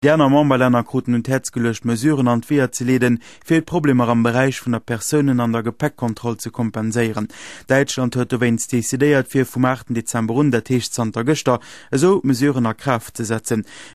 Here’s a recording of a news report in a mystery language.